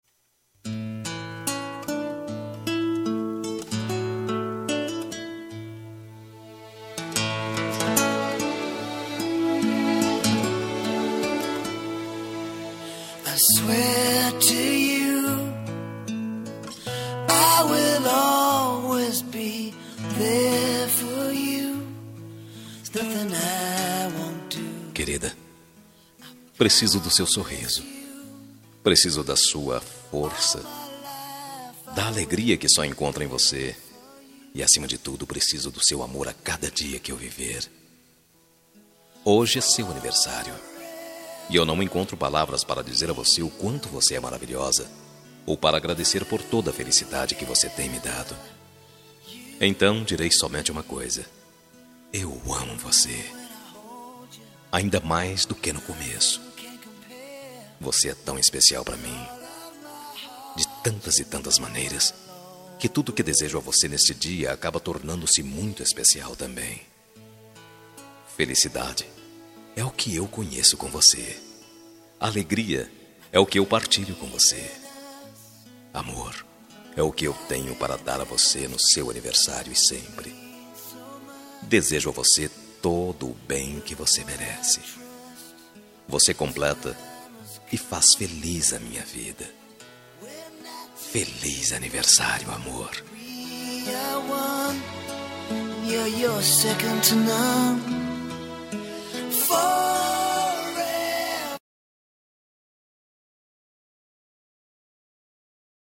Aniversário Esposa – Voz Masculino – Cód: 350328